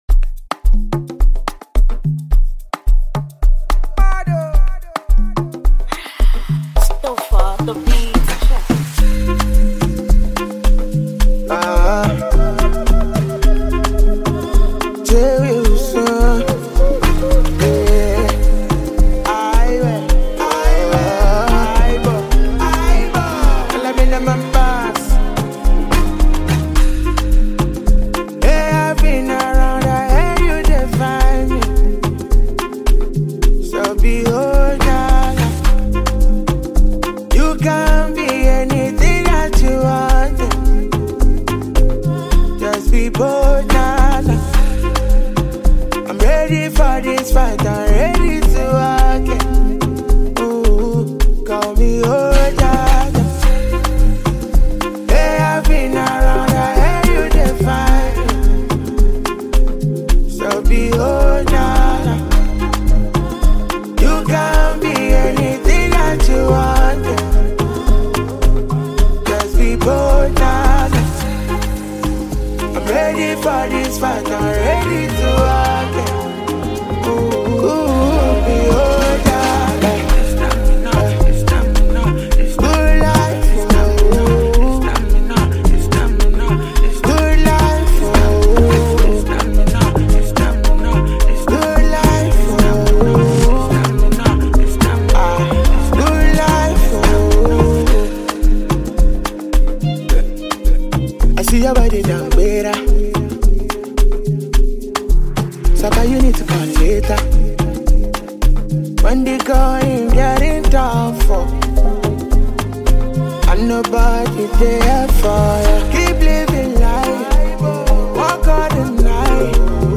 Nigerian sensational singer and songwriter
catchy single